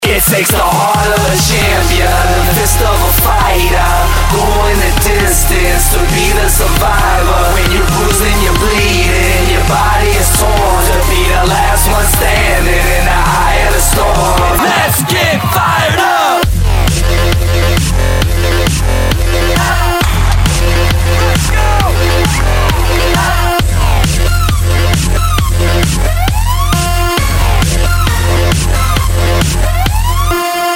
• Качество: 192, Stereo
electro
Новая заводная композиция